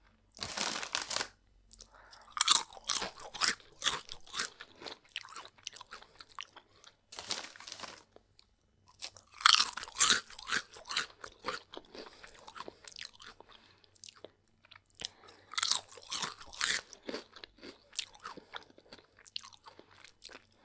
human